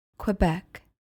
Pronounced: keh-BECK